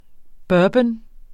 Udtale [ ˈbɶːbən ]